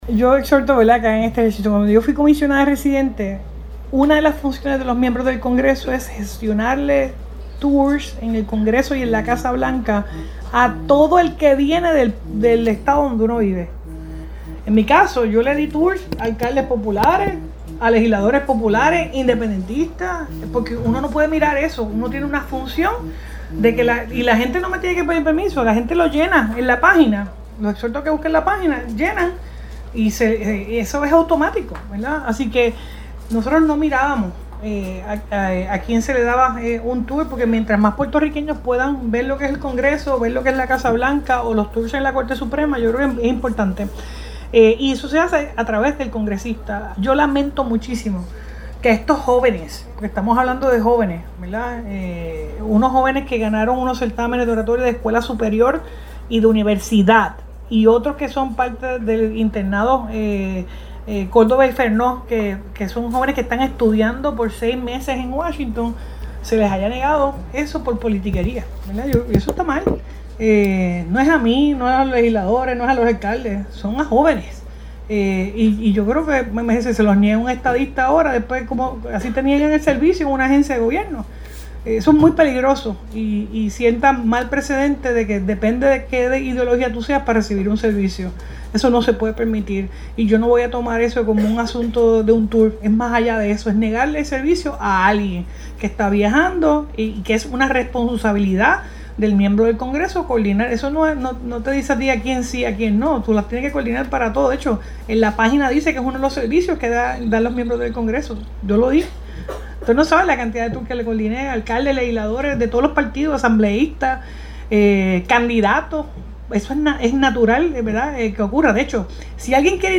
“Yo lamento que estos jóvenes que ganaron unos certámenes de oratoria de escuela superior y de universidad” el comisionado residente le haya negado un Tour dijo la gobernadora (sonido)